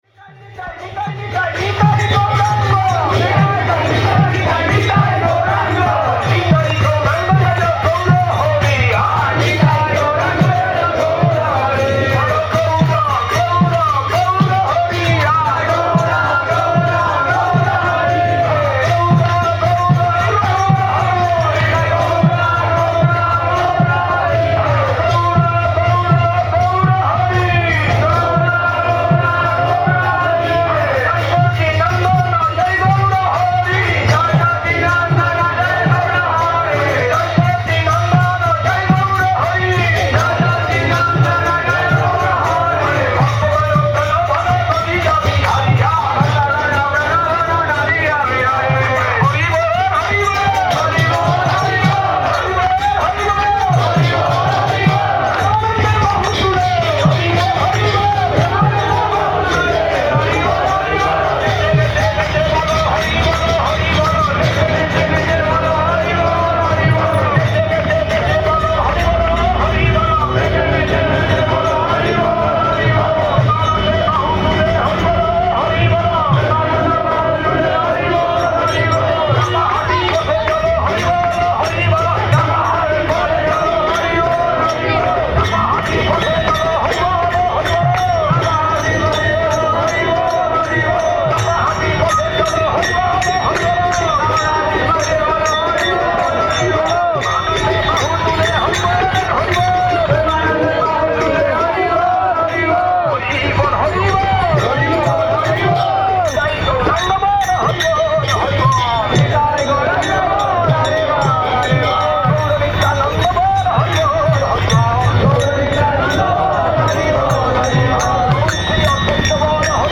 Place: SCSMath Nabadwip
Tags: Kirttan